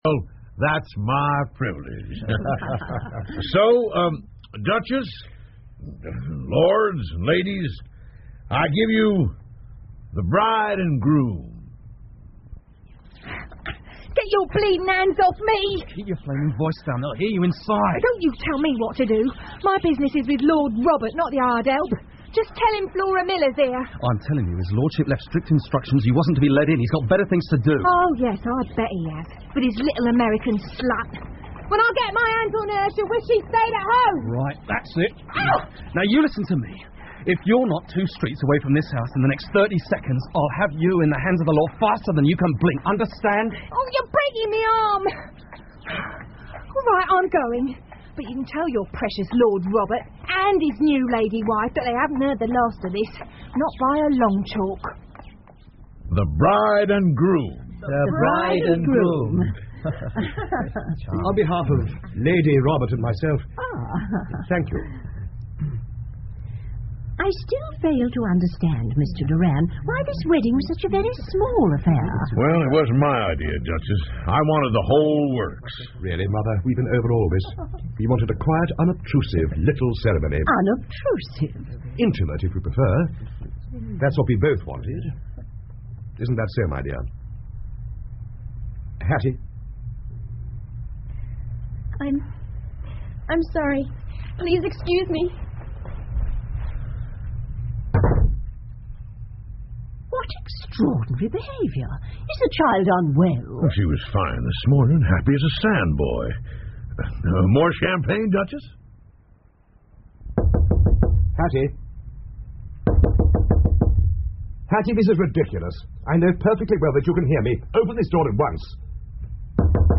福尔摩斯广播剧 The Noble Bachelor 2 听力文件下载—在线英语听力室